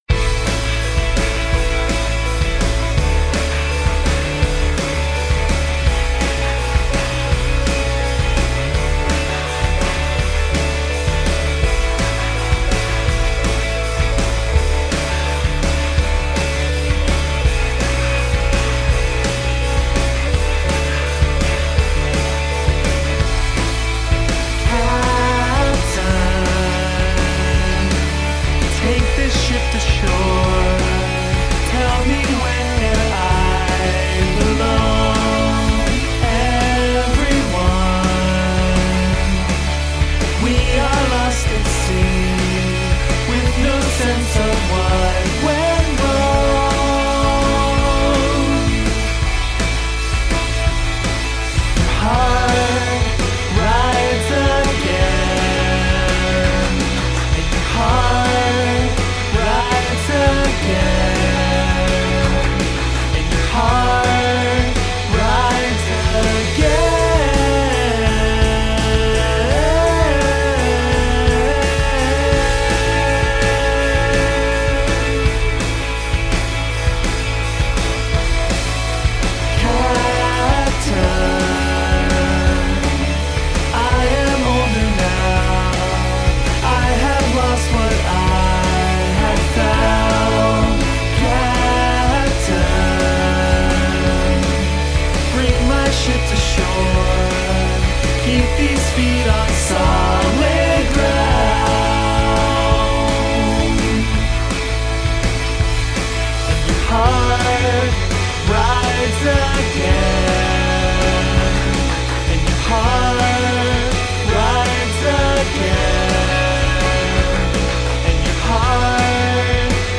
Sophisticated rock with emo undercurrents.